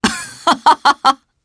Fluss-Vox_Happy3_jp_b.wav